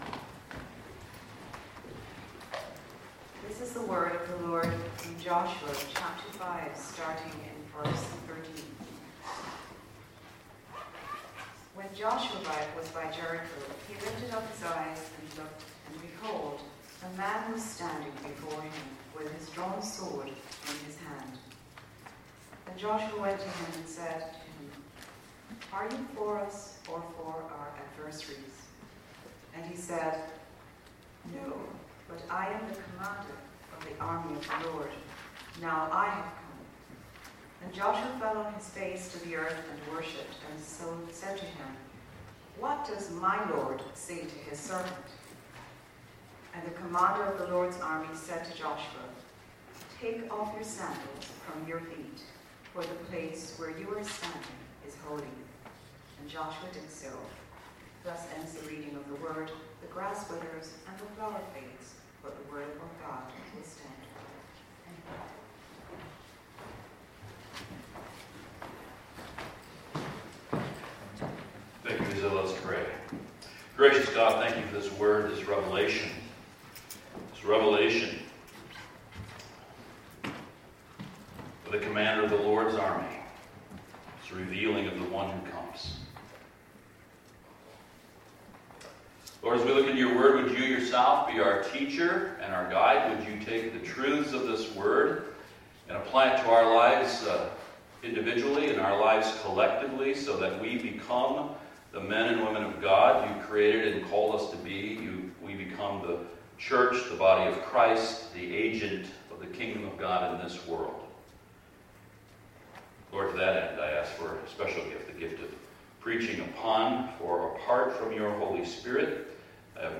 Series: Seeing Jesus in the Old Testament Passage: Joshua 5: 13-15 Service Type: Sunday Morning